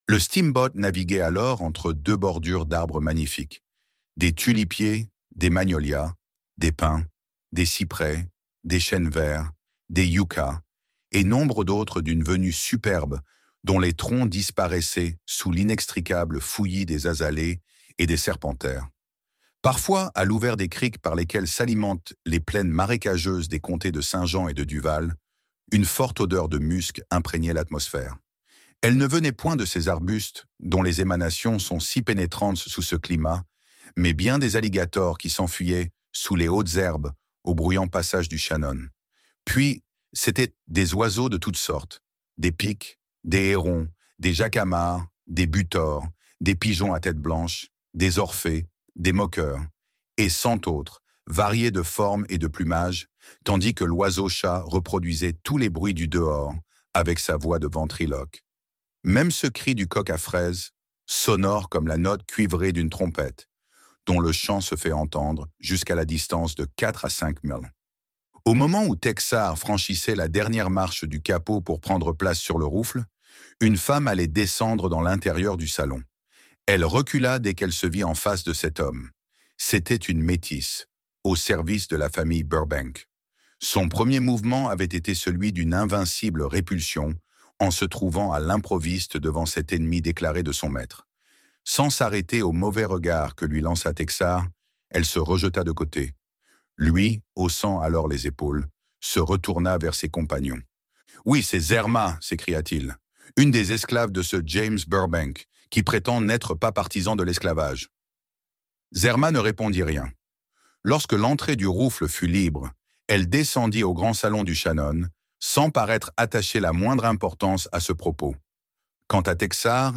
Nord contre Sud - Livre Audio